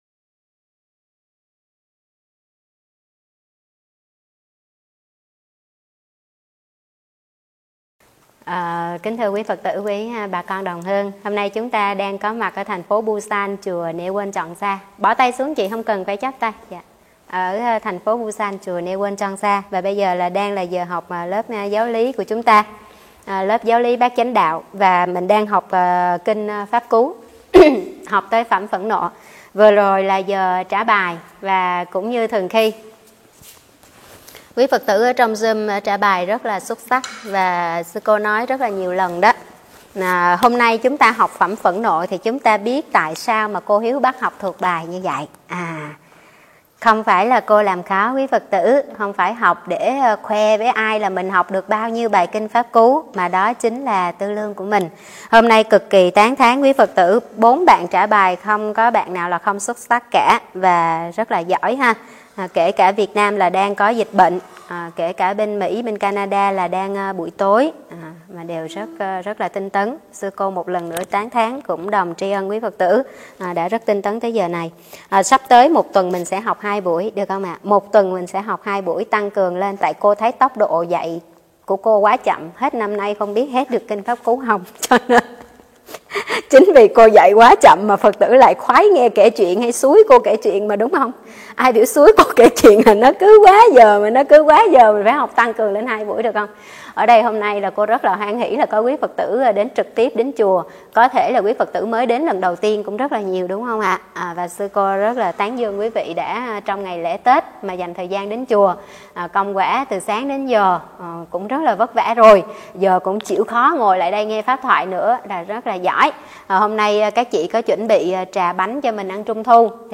Nghe mp3 thuyết pháp Cách chuyển hóa tâm sân